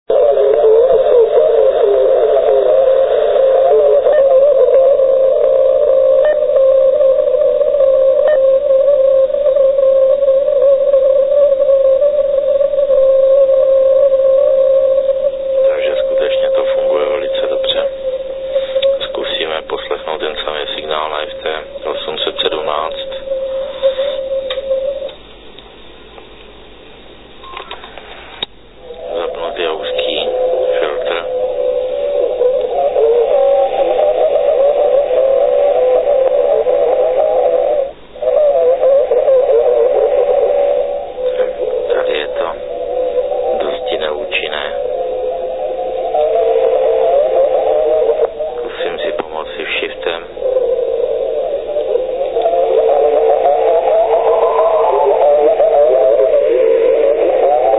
Byl poslouchán signál majáku OK0EN na 3600 KHz, kde do přijímacího spektra " výstřelově " zasahuje modulační obálka stanice, pracující blízko přijímaného kmitočtu a to módem SSB. Bez DSP pak máme veliké problémy slabý CW signál přečíst.
CW Signál zarušený SSB přijímaný pomocí DSP